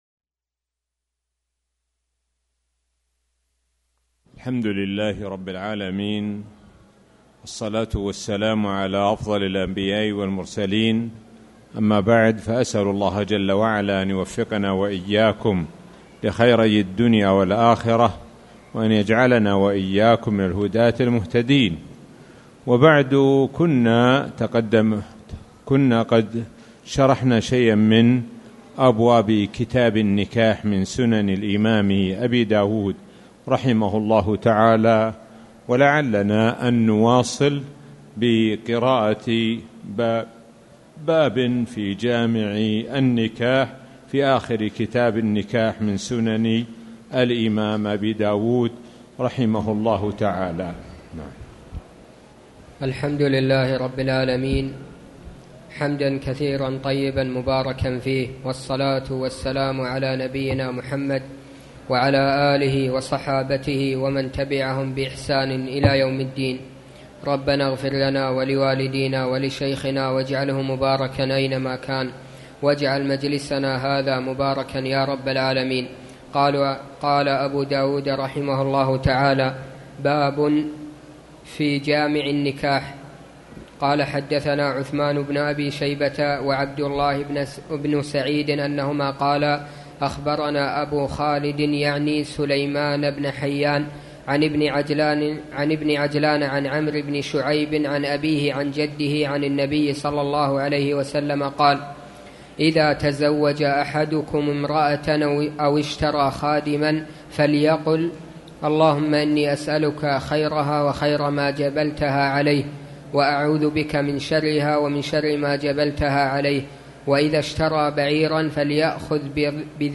تاريخ النشر ٢ ذو القعدة ١٤٣٨ هـ المكان: المسجد الحرام الشيخ: معالي الشيخ د. سعد بن ناصر الشثري معالي الشيخ د. سعد بن ناصر الشثري كتاب النكاح